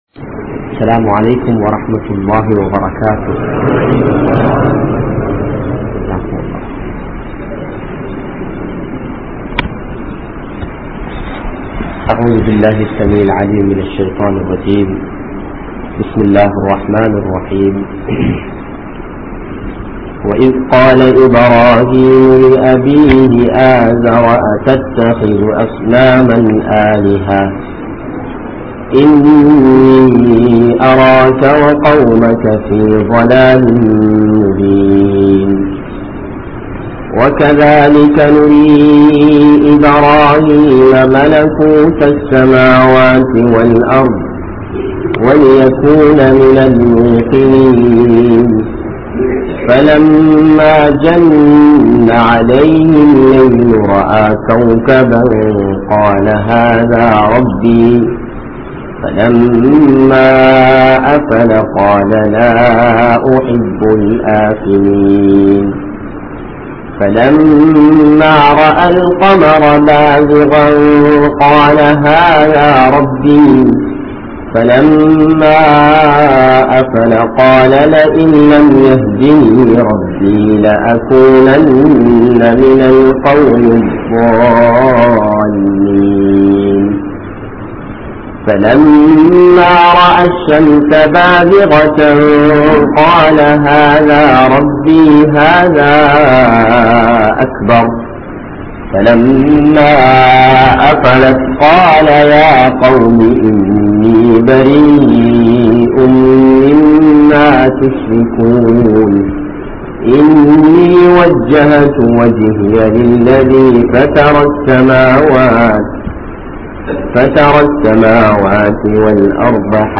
Ibrahim(Alai)Avarhalin Mun Maathiri (இப்றாஹிம்(அலை)அவர்களின் முன்மாதிரி) | Audio Bayans | All Ceylon Muslim Youth Community | Addalaichenai